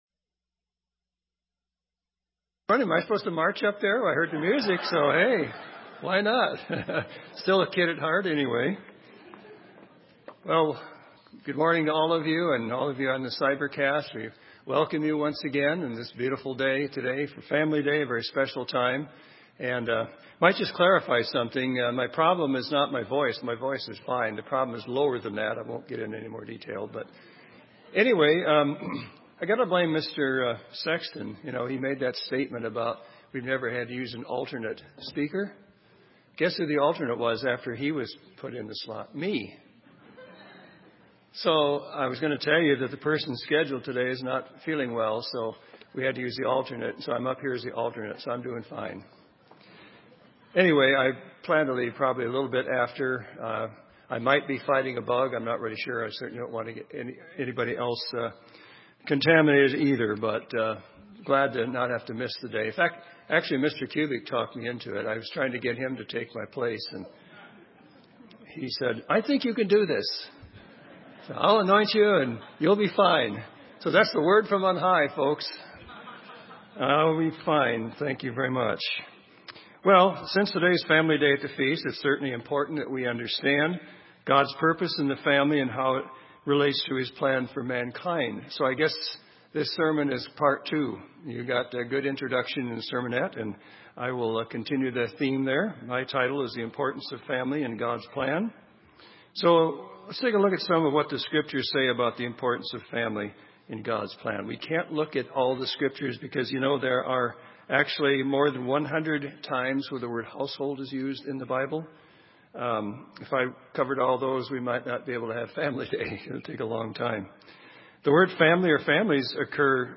This sermon was given at the Bend, Oregon 2014 Feast site.